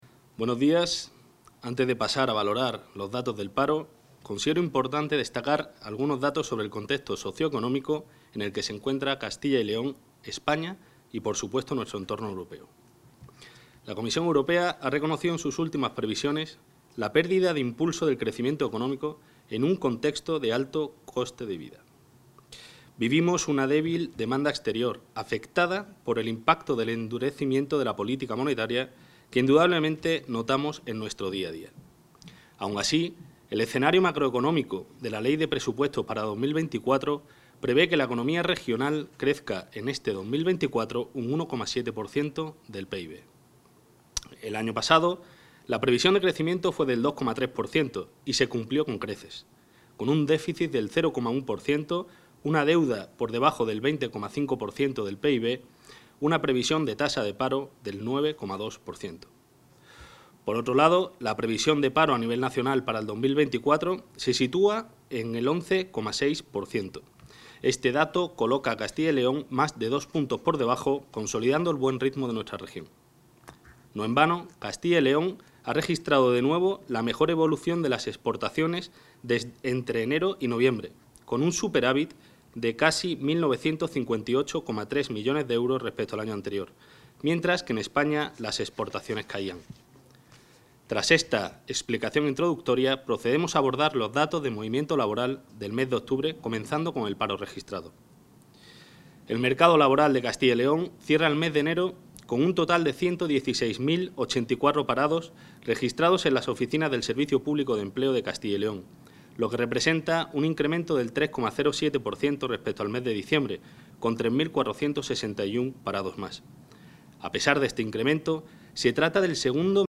Valoración del gerente del Ecyl.
El gerente del Servicio Público de Empleo de Castilla y León (Ecyl), Álvaro Ramos-Catalina Ysasi, ha valorado hoy los datos de paro registrado correspondientes al mes de enero de 2024.